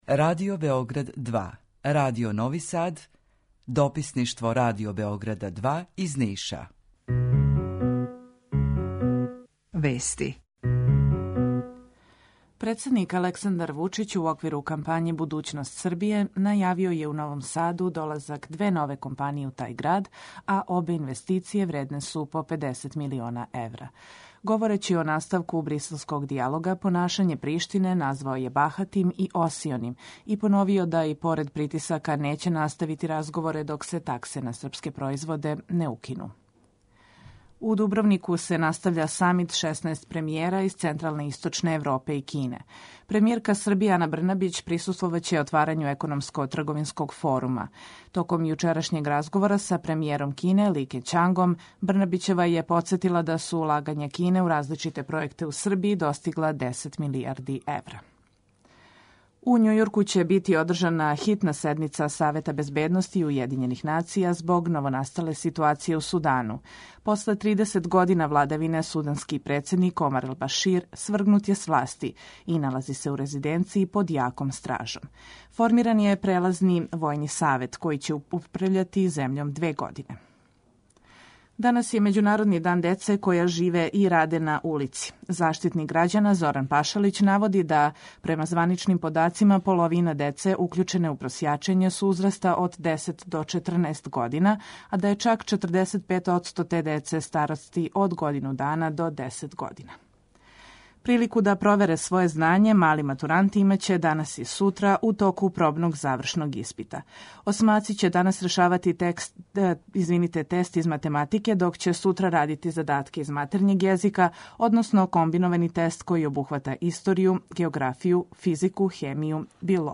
У два сата, ту је и добра музика, другачија у односу на остале радио-станице.